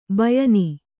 ♪ bayani